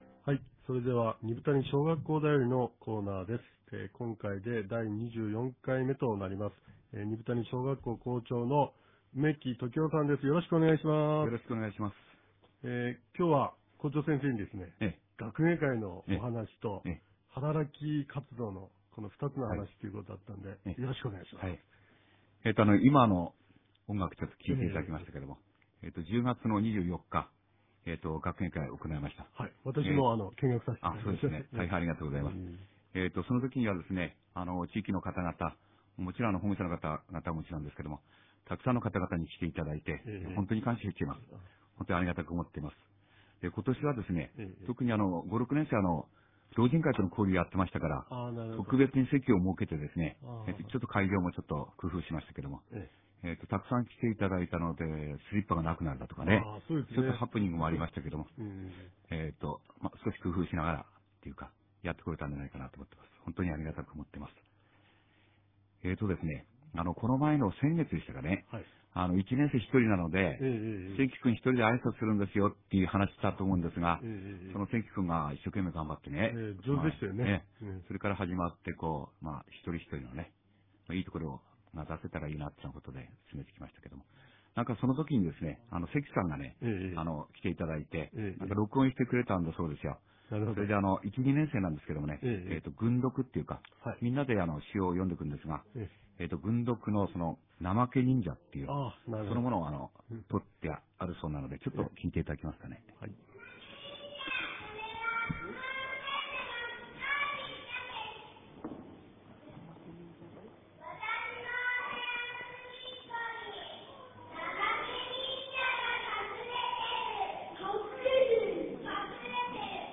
■カムイユカﾗ（神の謡）